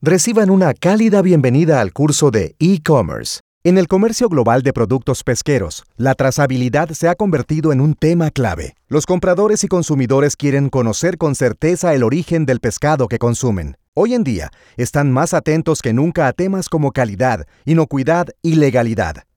Voice actor Actor de voz y locuctor home studio español Neutro
kolumbianisch
Sprechprobe: Sonstiges (Muttersprache):